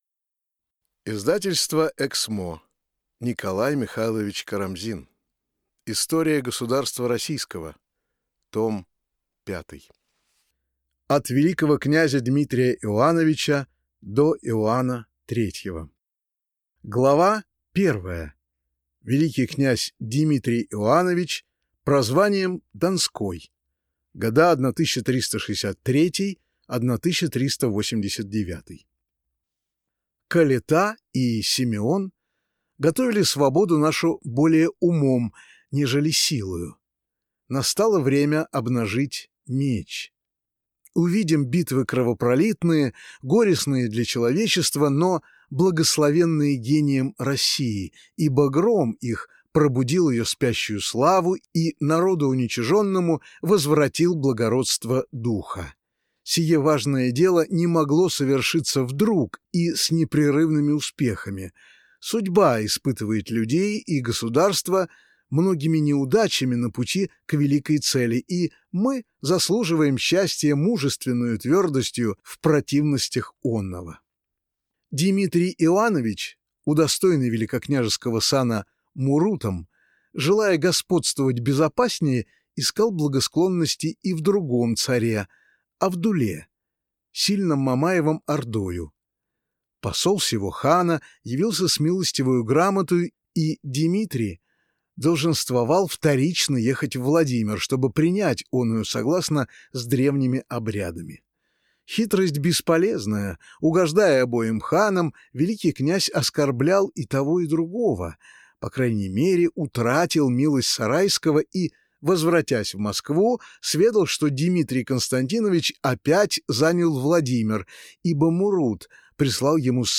Аудиокнига История государства Российского Том 5 | Библиотека аудиокниг